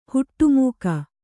♪ huṭṭu mūka